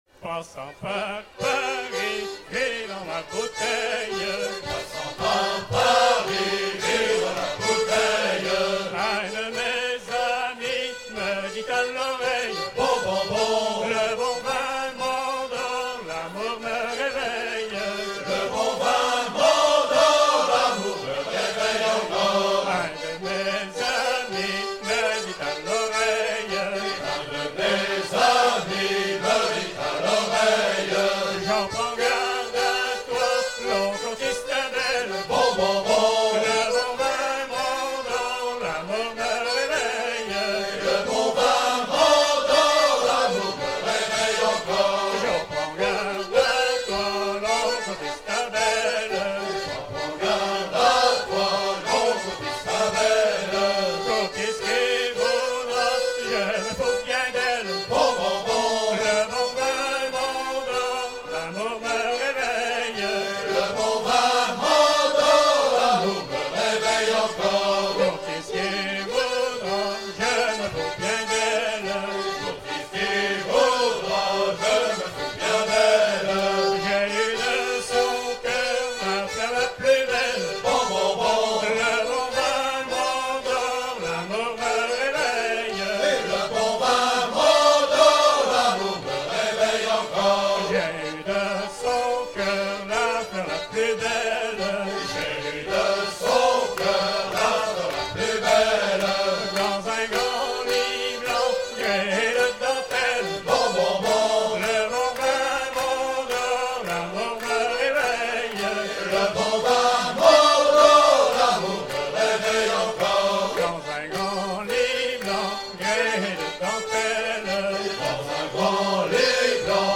à virer au cabestan
Genre laisse
Pièce musicale éditée